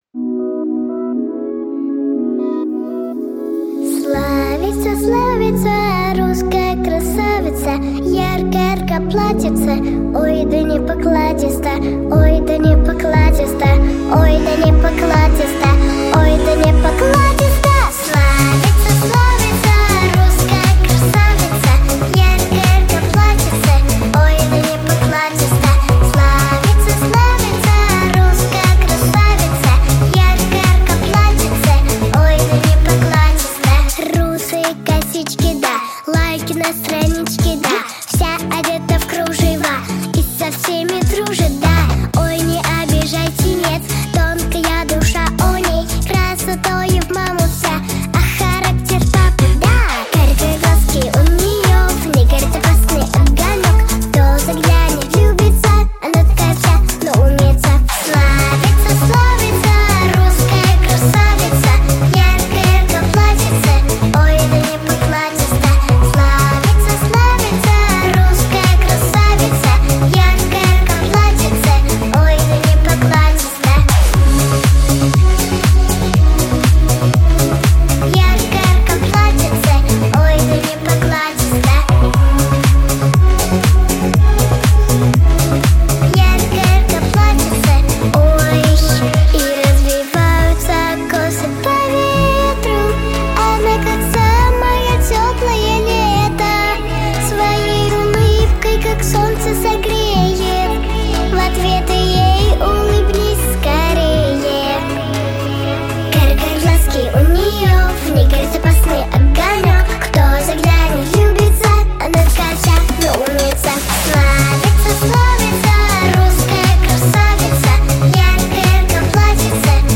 • Качество: Хорошее
• Жанр: Детские песни
народный мотив